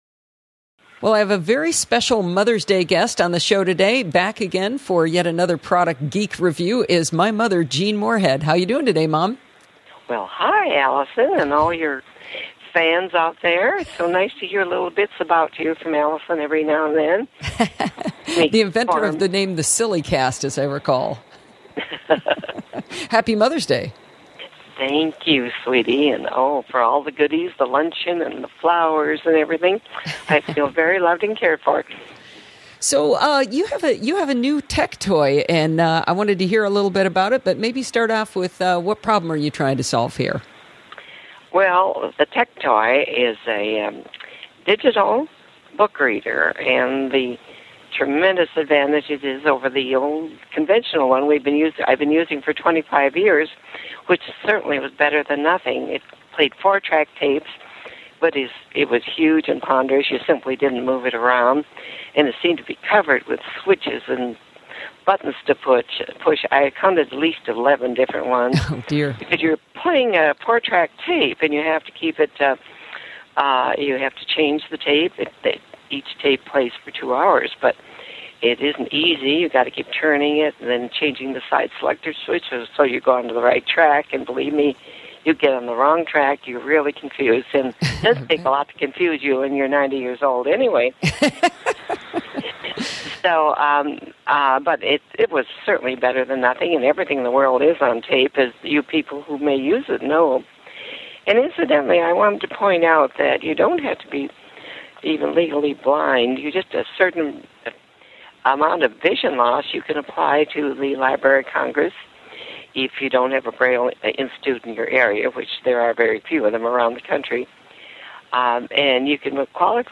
I pulled out one of her reviews from May 9th, 2010 and I’d like to play it for you:
I have to say I teared up listening to her voice after all these years but then I had to laugh when she made fun of me to you.